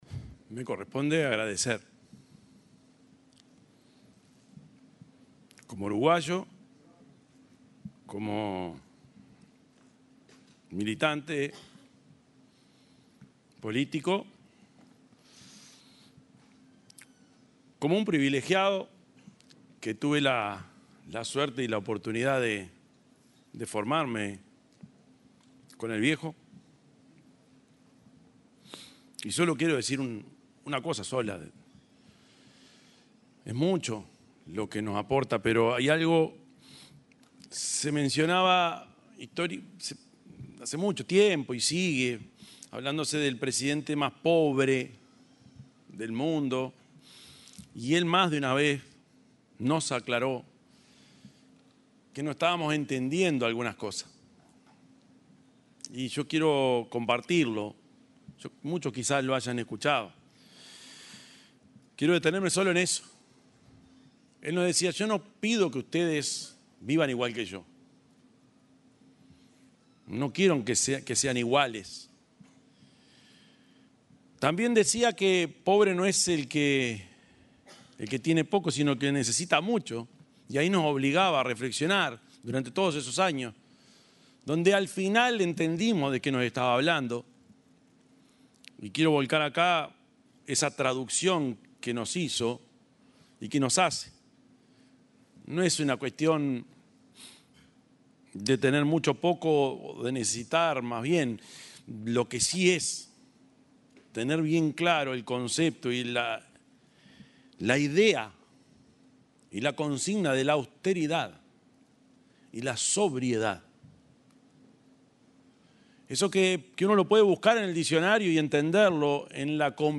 Palabras del presidente Yamandú Orsi
El presidente de la República, Yamandú Orsi, se refirió al legado del exmandatario José Mujica, durante un homenaje que le realizó el Remarque